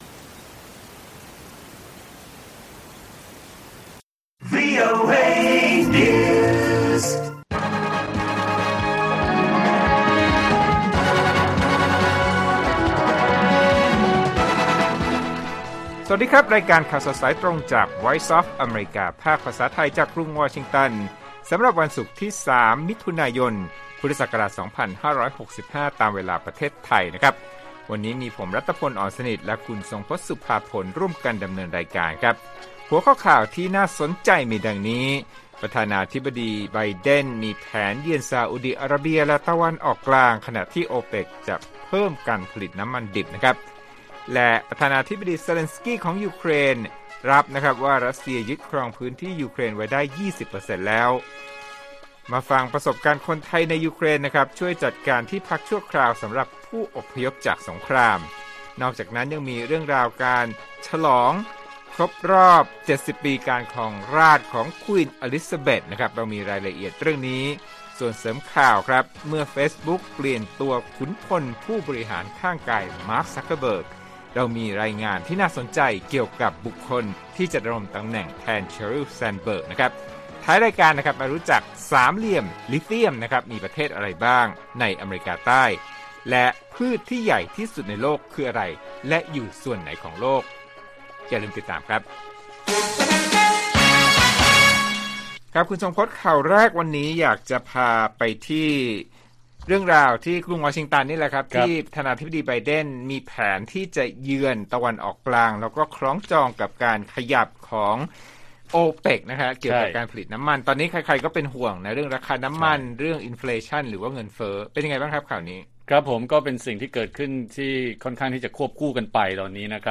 ข่าวสดสายตรงจากวีโอเอไทย ศุกร์ 3 มิ.ย. 65